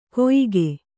♪ koyige